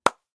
handclap.ogg